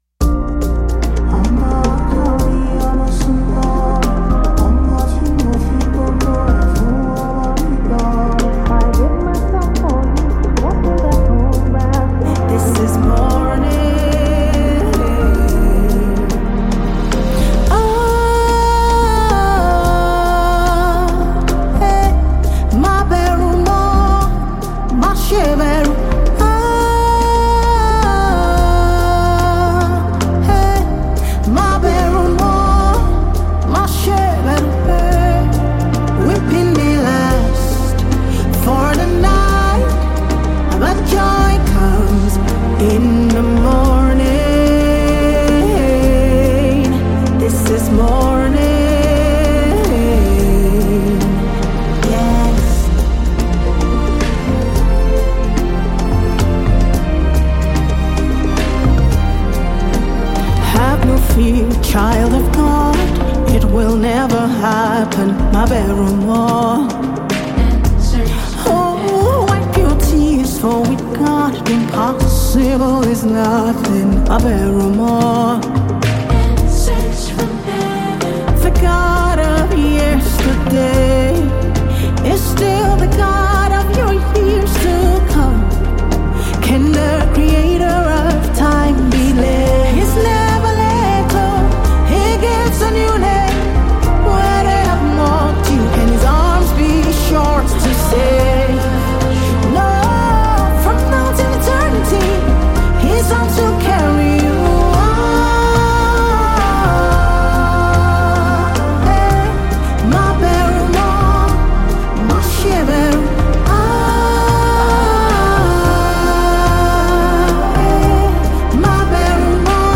” blends contemporary gospel with African sounds